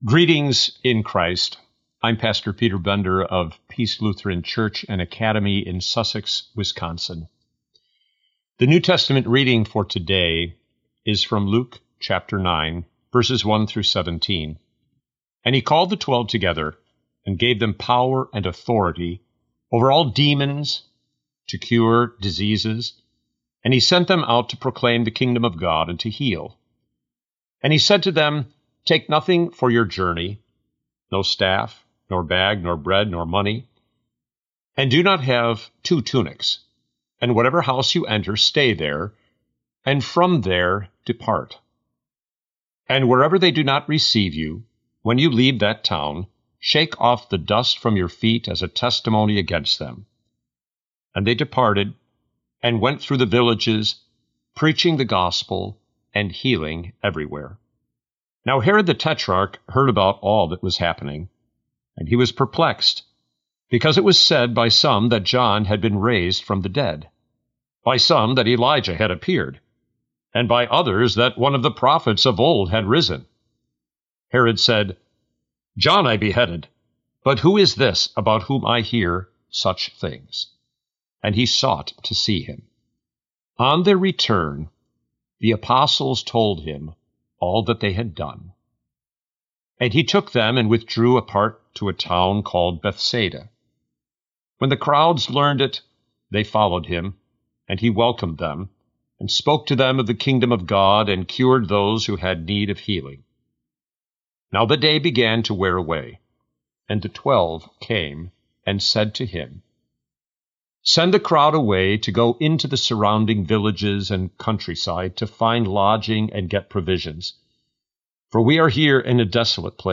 Morning Prayer Sermonette: Luke 9:1-17
Hear a guest pastor give a short sermonette based on the day’s Daily Lectionary New Testament text during Morning and Evening Prayer.